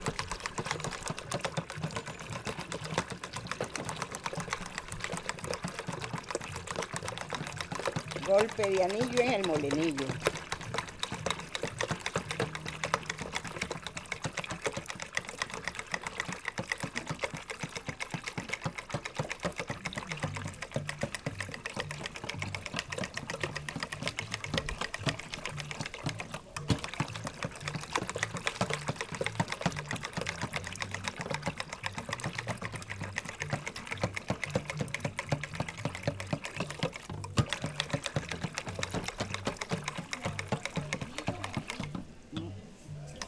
Golpe anillo en el molino GUANACASTE